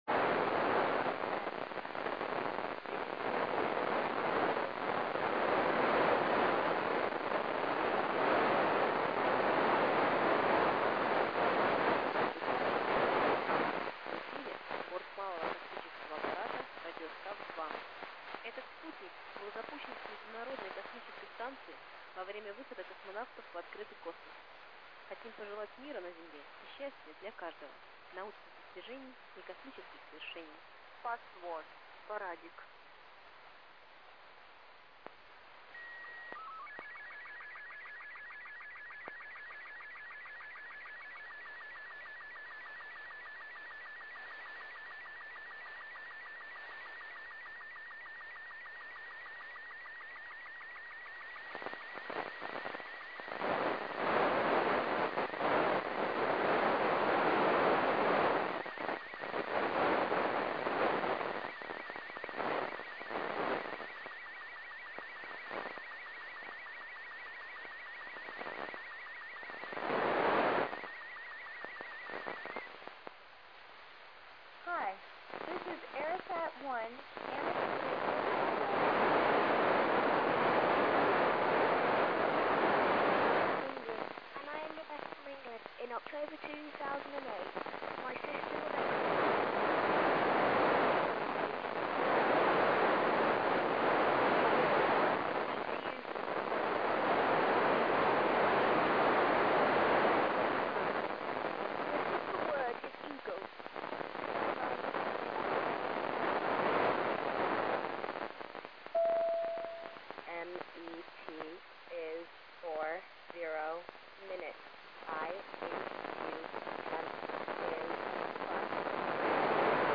Начало » Записи » Записи радиопереговоров - МКС, спутники, наземные станции